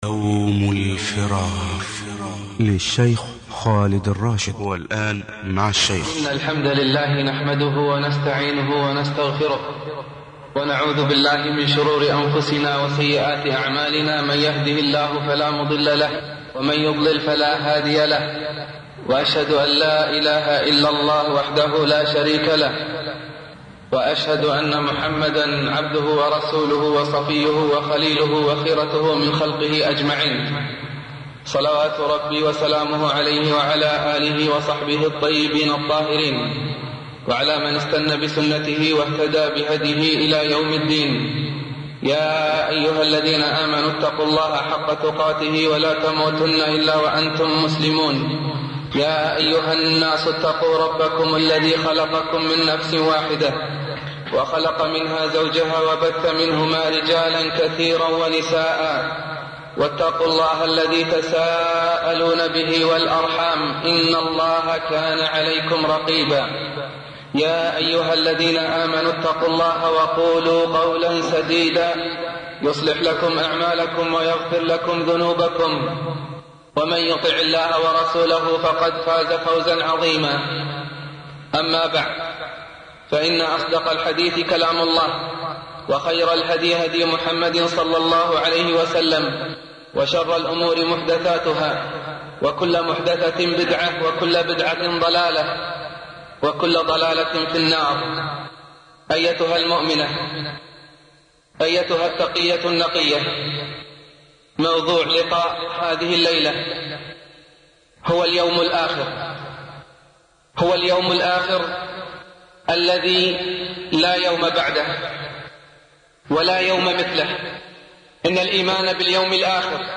الخطبة الأولى